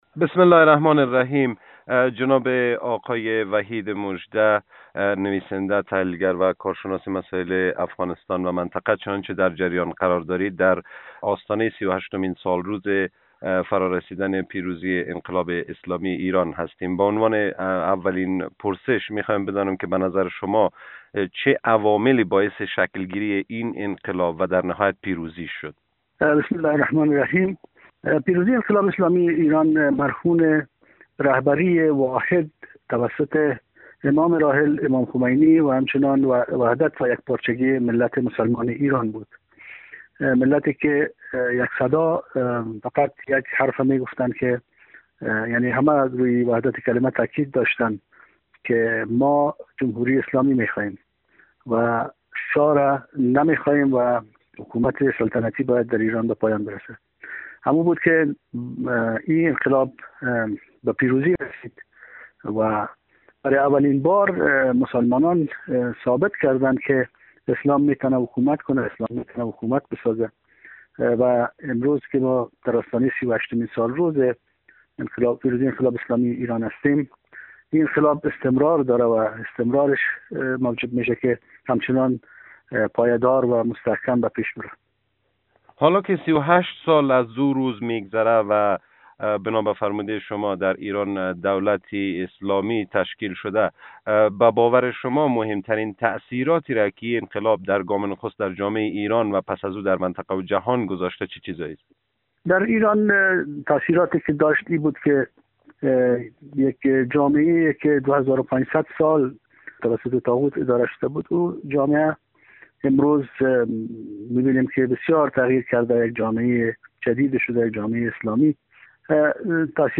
در گفت و گو با خبرنگار رادیو دری در کابل